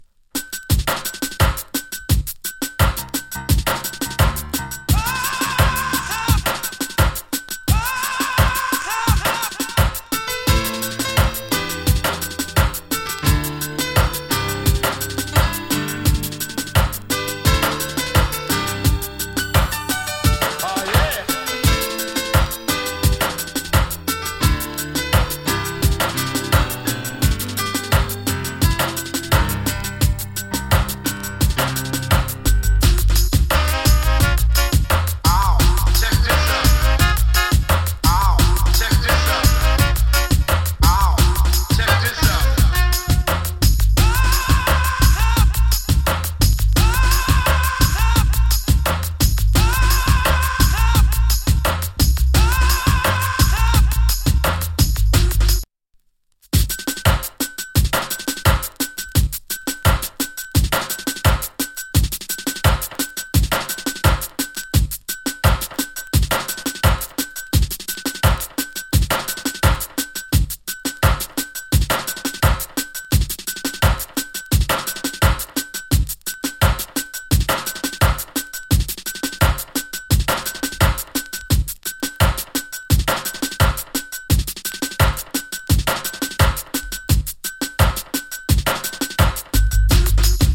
MEDLEY STYLE の FINE INST !